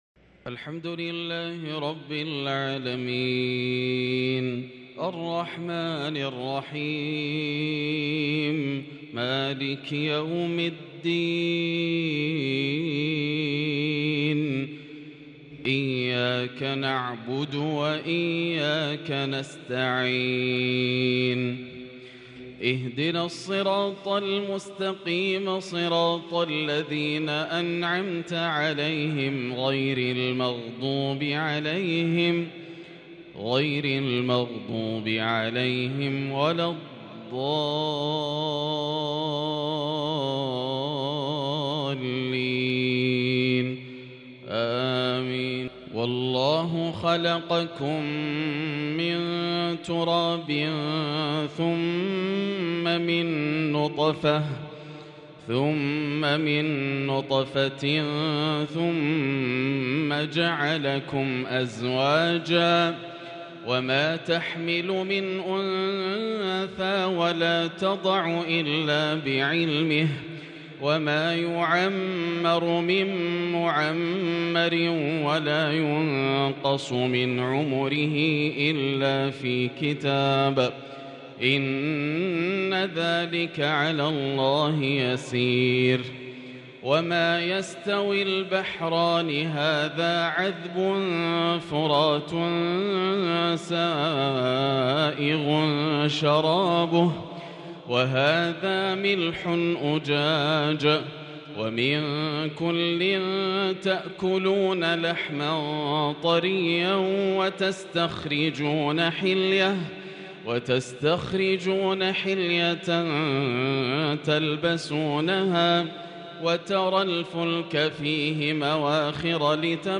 تلاوة بديعة من سورة فاطر - عشاء الإثنين 30-2-144هـ > عام 1444 > الفروض - تلاوات ياسر الدوسري